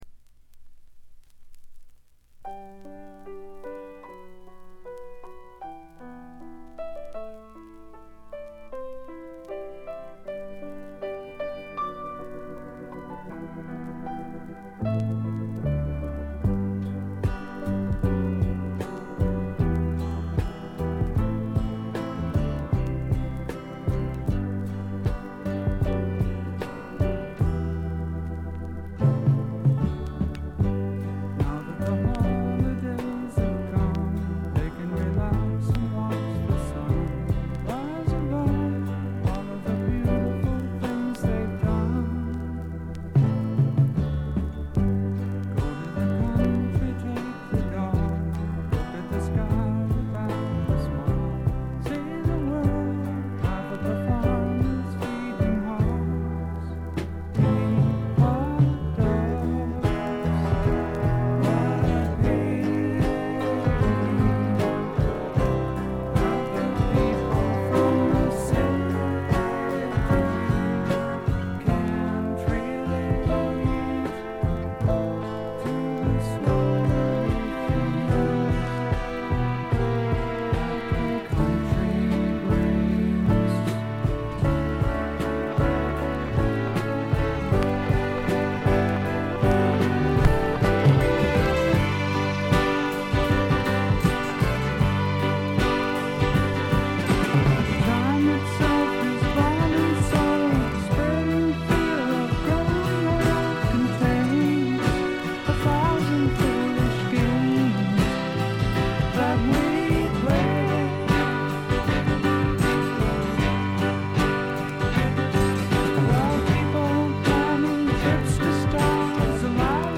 静音部ところどころでチリプチ。散発的なプツ音少々。
試聴曲は現品からの取り込み音源です。